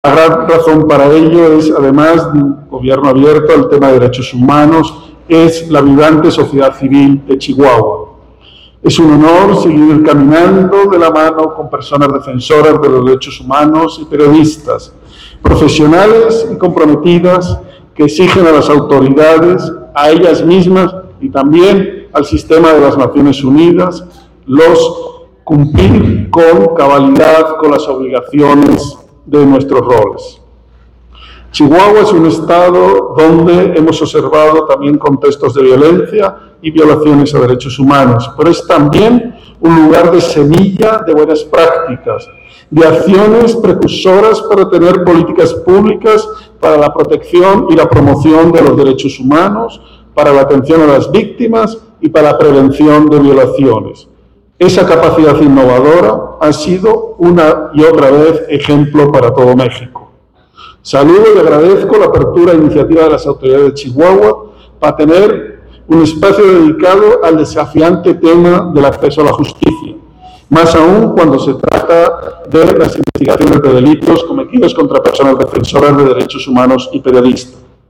AUDIO: JESÚS PEÑA PALACIOS,. REPRESENTANTE ADJUNTO EN MÉXICO DEL ALTO COMISIONADO DE LAS NACIONES UNIDAS (ONU) PARA LAS DERECHOS HUMANOS